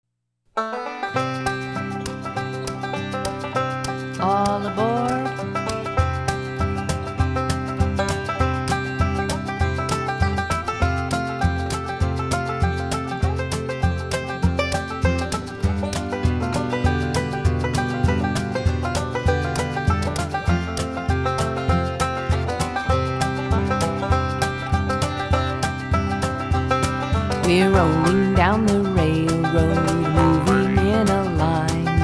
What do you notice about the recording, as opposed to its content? Demo MP3